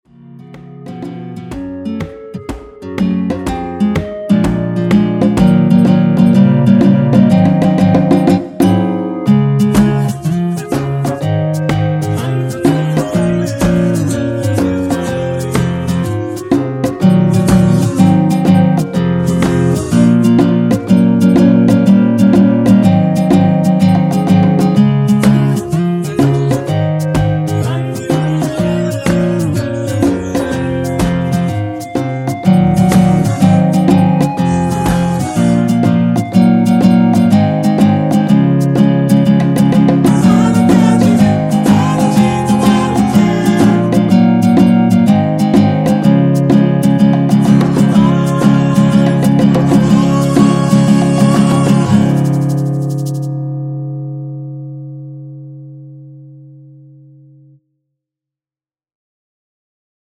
전주 없이 시작 하는곡이라 노래 하시기 편하게 전주 2마디 많들어 놓았습니다.(일반 MR 미리듣기 확인)
원키에서(-3)내린 멜로디와 코러스 포함된 MR입니다.(미리듣기 확인)
Db
앞부분30초, 뒷부분30초씩 편집해서 올려 드리고 있습니다.